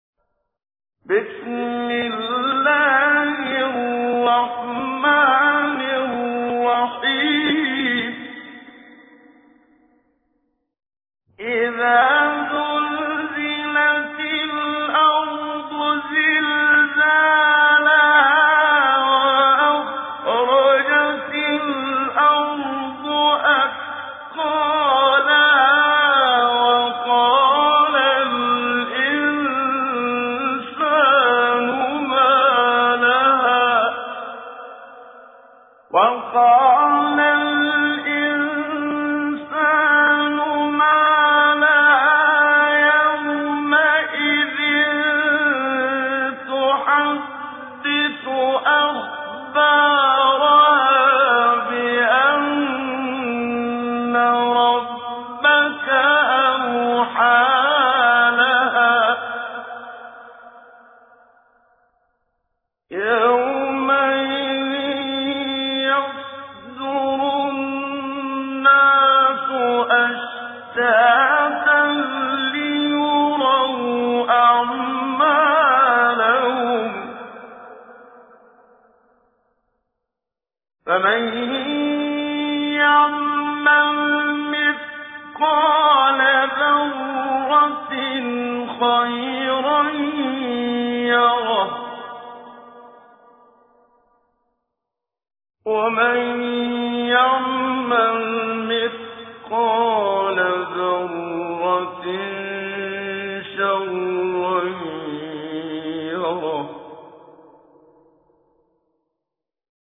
تجويد
سورة الزلزلة الخطیب: المقريء الشيخ محمد صديق المنشاوي المدة الزمنية: 00:00:00